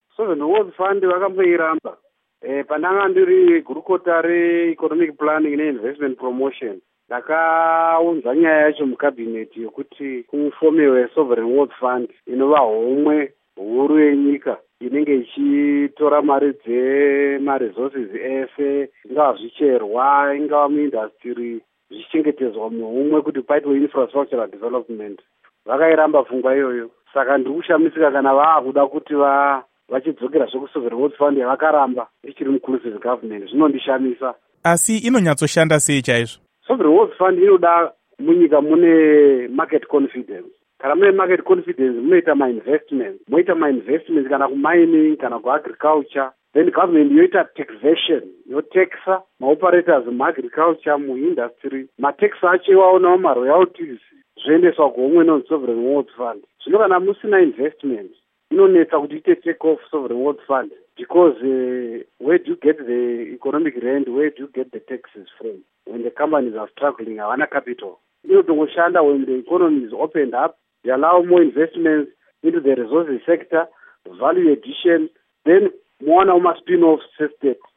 Hurukuro naVaTapiwa Mashakada